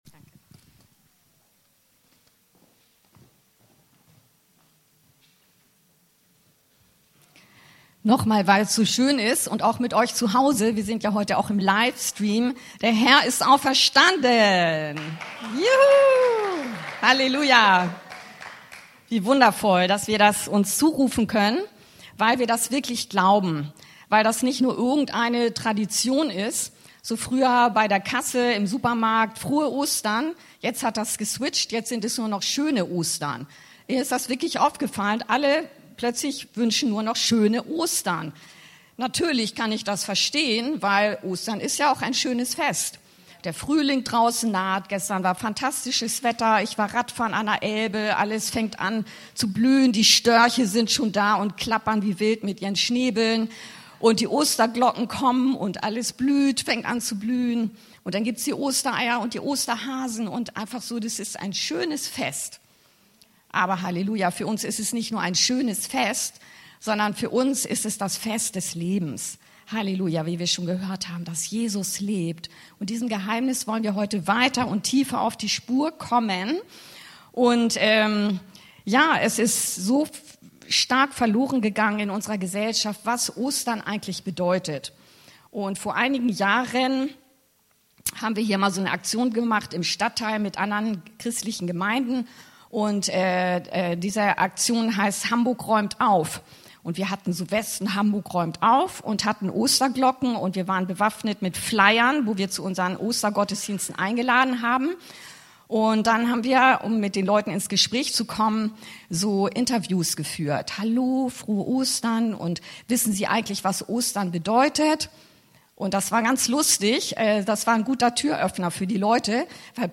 Den Auferstandenen sehen und gehen! - Predigt zu Ostern